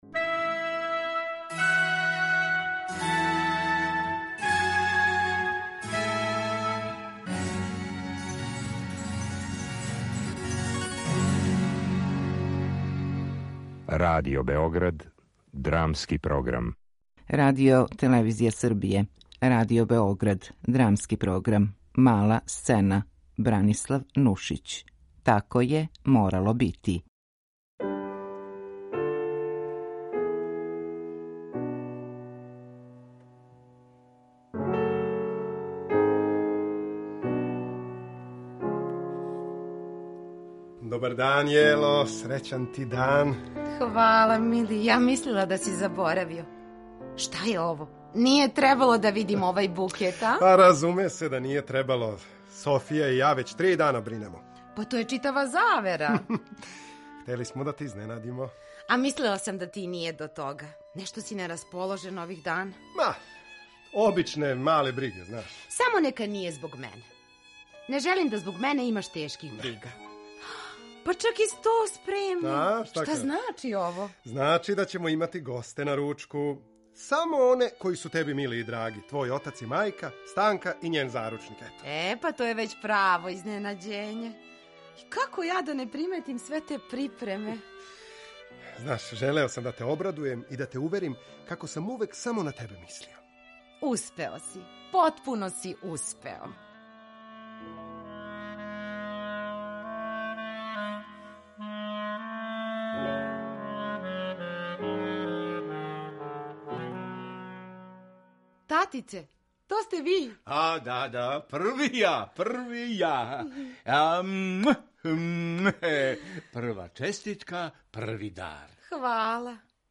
Радио-адаптација трочиног комада Бранислава Нушића, иначе премијерно изведеног 1900.